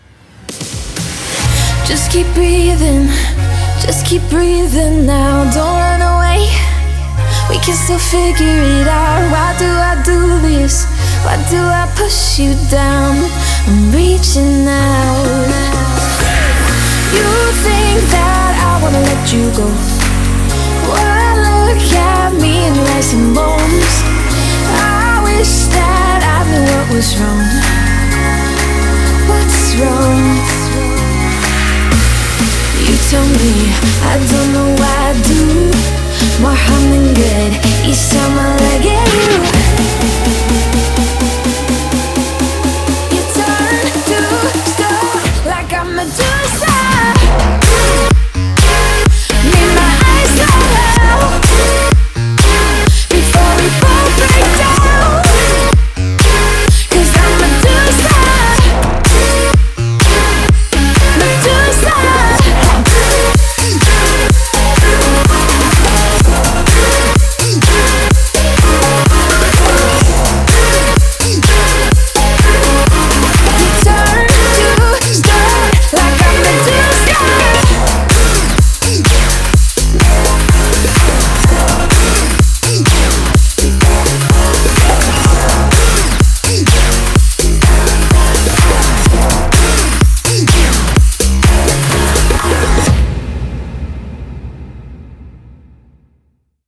BPM126
MP3 QualityMusic Cut